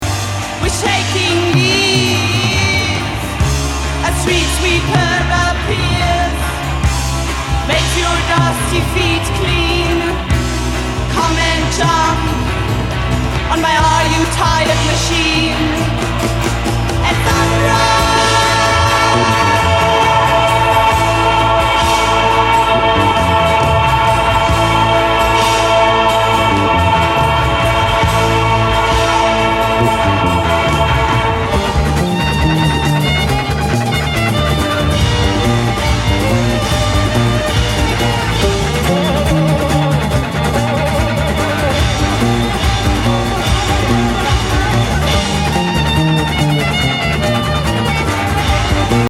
強烈なエスノ・フリージャズ
ユーロ・プログレなテイストも
サイケ～HR傑作!